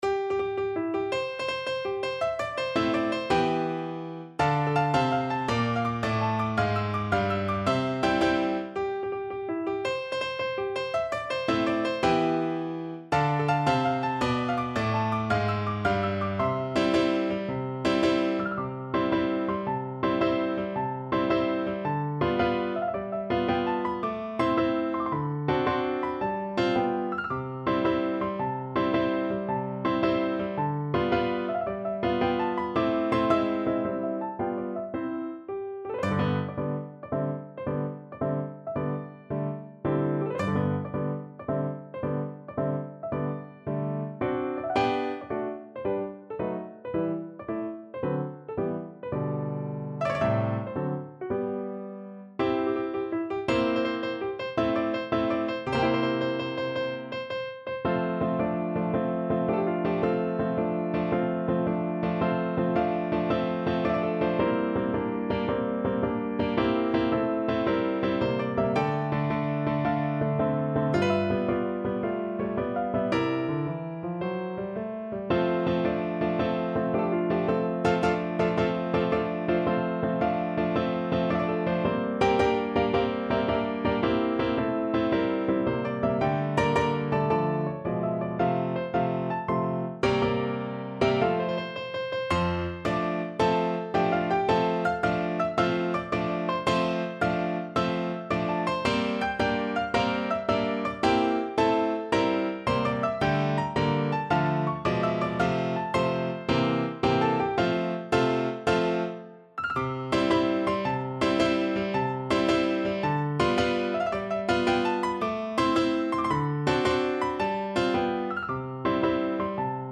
6/8 (View more 6/8 Music)
March .=c.110
Classical (View more Classical Voice Music)